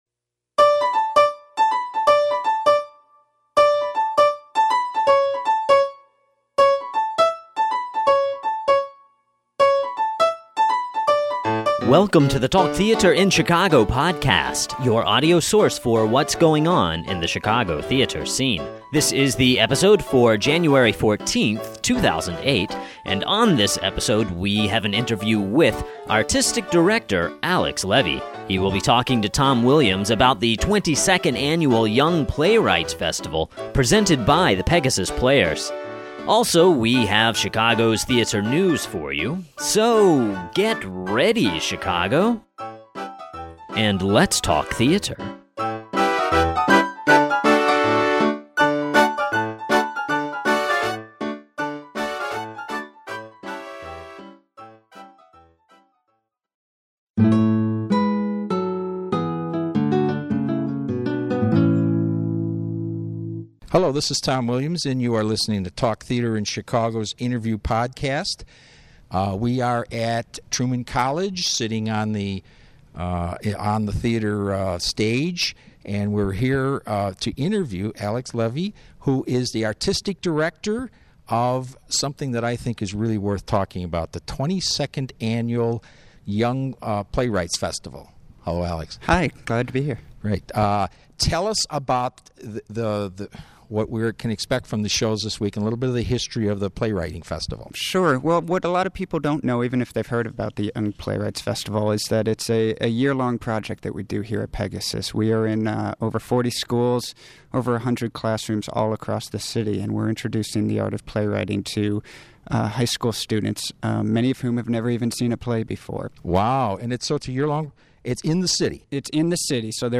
Interview Podcast